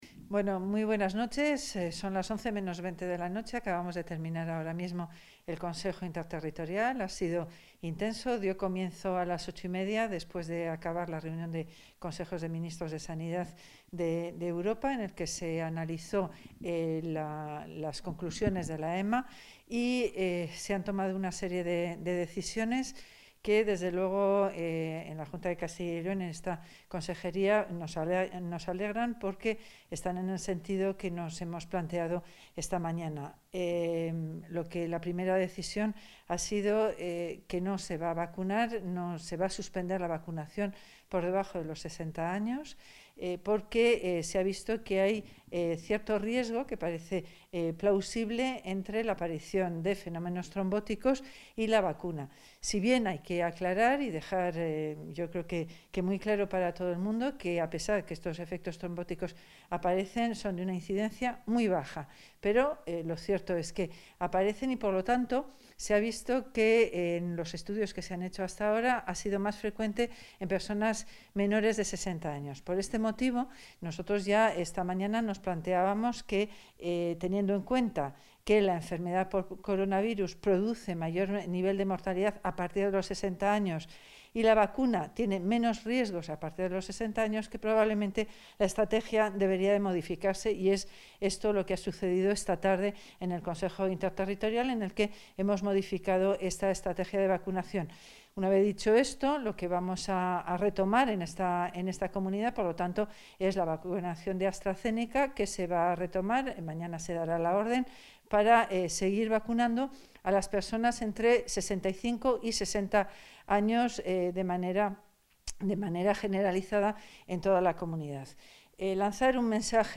Declaraciones de la consejera de Sanidad.